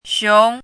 chinese-voice - 汉字语音库
xiong2.mp3